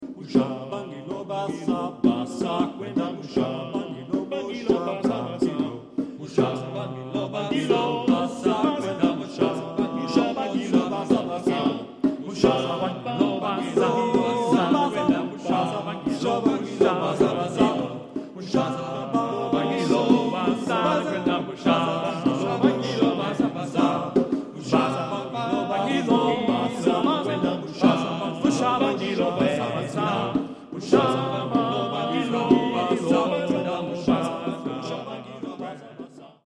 Genre-Stil-Form: Kanon ; Jazz ; weltlich
Chorgattung:  (4-stimmiger gleichstimmig )
Instrumente: Trommeln (2)
Tonart(en): D-Dur